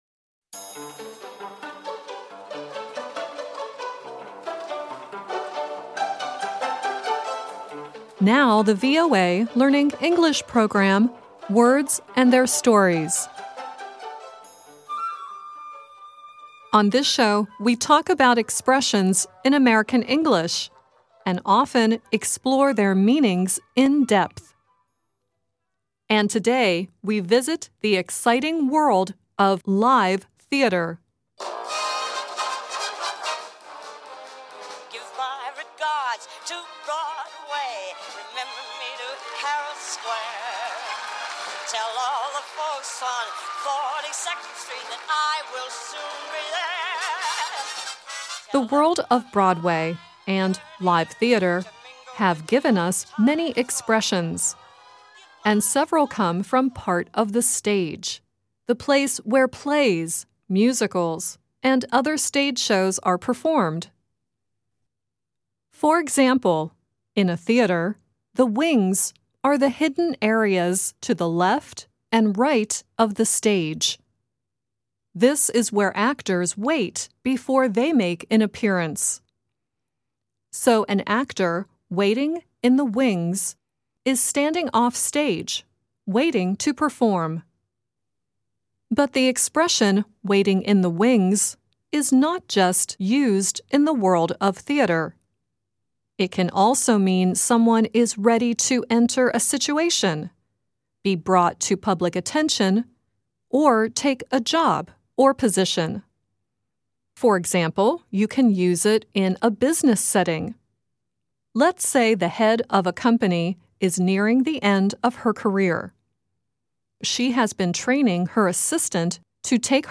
The songs in order of appearance are: Judy Garland singing "Give My Regards to Broadway," cast members of the musical Curtains singing "Show People" and Ben E. King sings "Stand By Me" at the end.